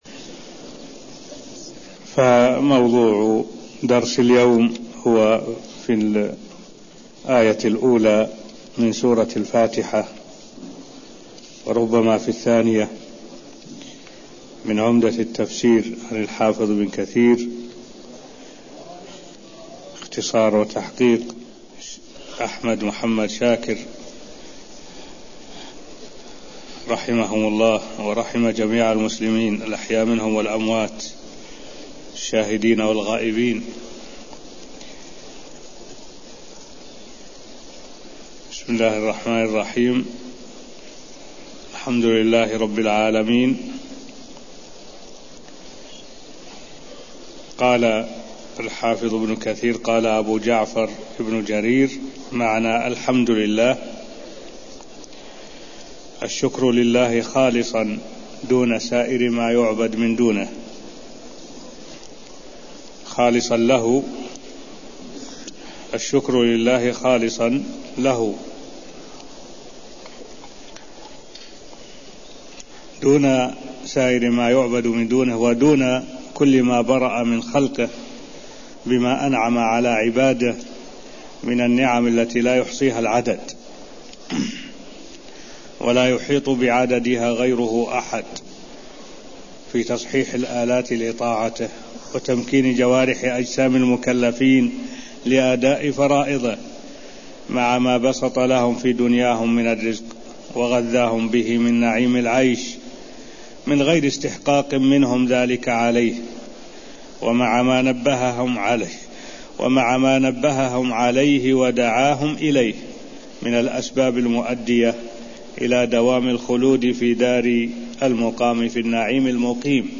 المكان: المسجد النبوي الشيخ: معالي الشيخ الدكتور صالح بن عبد الله العبود معالي الشيخ الدكتور صالح بن عبد الله العبود تفسير الآية الاولى من سورة الفاتحة (0010)